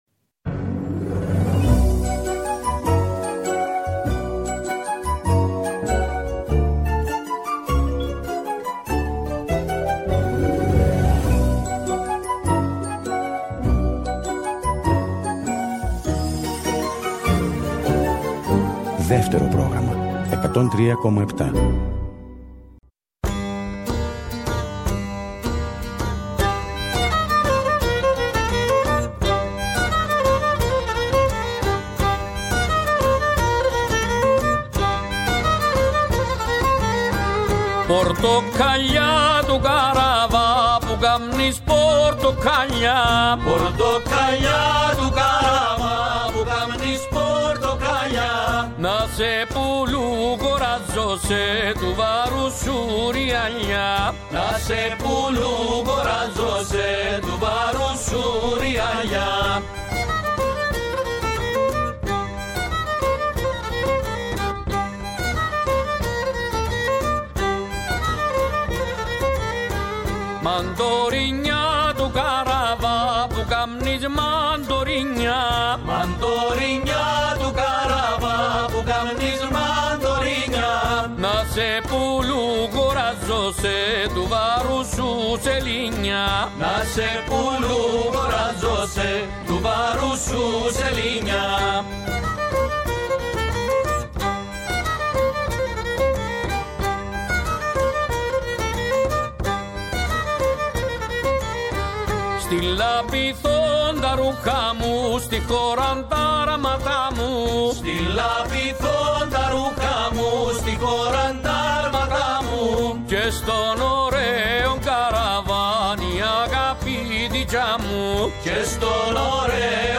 «Έχει η ζωή γυρίσματα» Μία δίωρη ραδιοφωνική περιπλάνηση, τα πρωινά του Σαββατοκύριακου.
Συνεντεύξεις